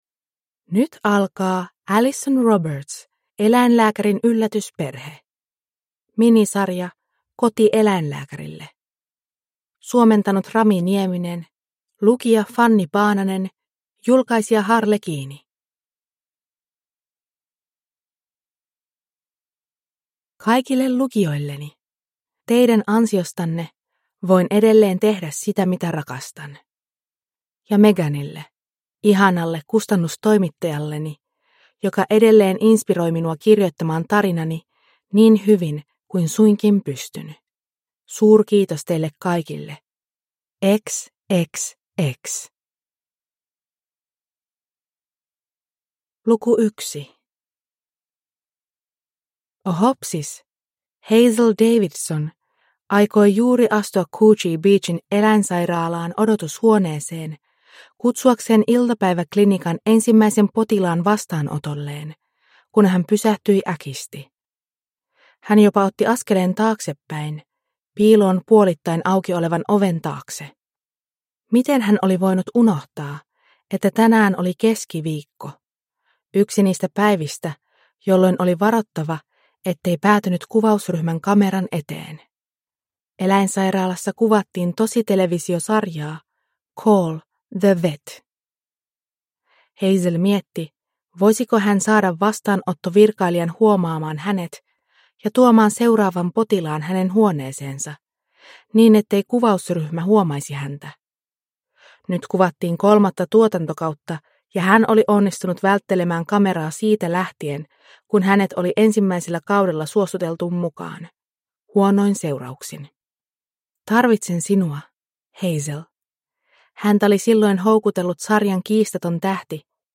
Eläinlääkärin yllätysperhe (ljudbok) av Alison Roberts